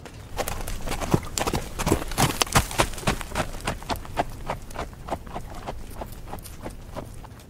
Здесь собраны натуральные записи: от мягкого перестука копыт до мощного рёва во время гона.
Шум бегущего северного оленя